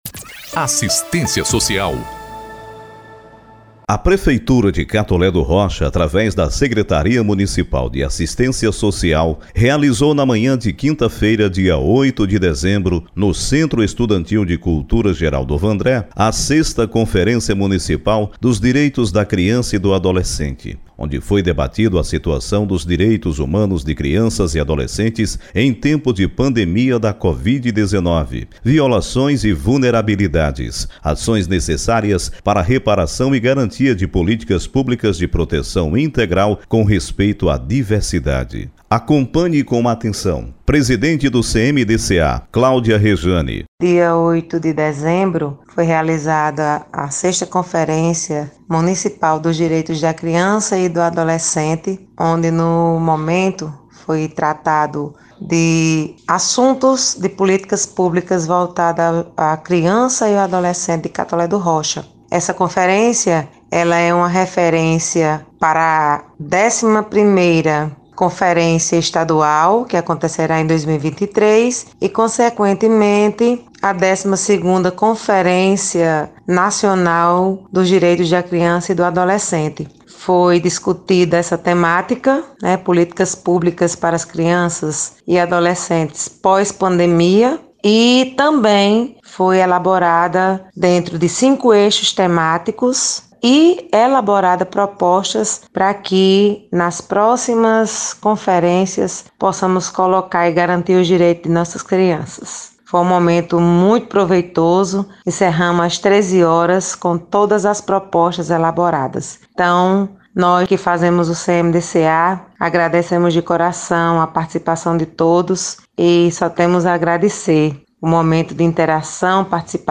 Na manhã de quinta-feira (08/12), aconteceu no Centro Estudantil de Cultura ‘Geraldo Vandré’ a 6ª Conferência Municipal dos Direitos da Criança e do Adolescente de Catolé do Rocha.
6a-Conferencia-Municipal-dos-Direitos-da-Crianca-e-do-Adolescente.mp3